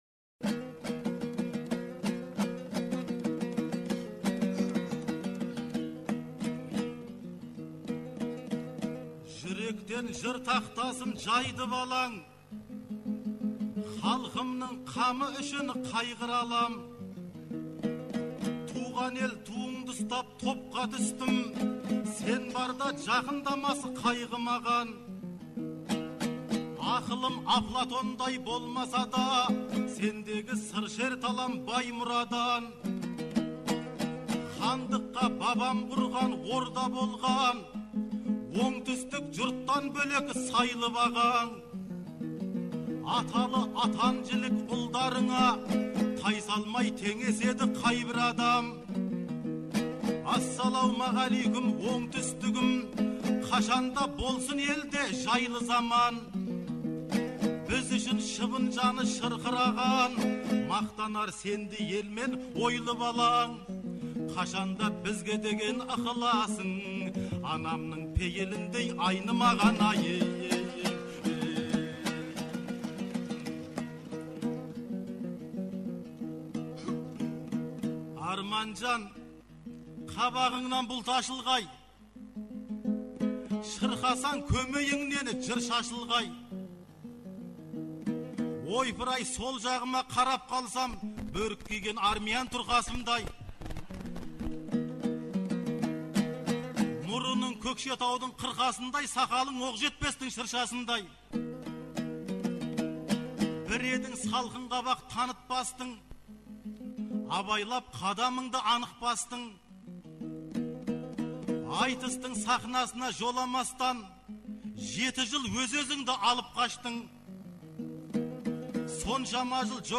Наурыздың 15-16-сы күндері Шымкентте республикалық «Наурыз» айтысы өтті. 2004 жылдан бері тұрақты өтіп келе жатқан бұл айтыс биыл Төле бидің 350 және Абылай ханның 300 жылдықтарына арналды.